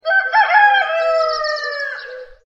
دانلود صدای خروس در جنگل از ساعد نیوز با لینک مستقیم و کیفیت بالا
جلوه های صوتی
برچسب: دانلود آهنگ های افکت صوتی انسان و موجودات زنده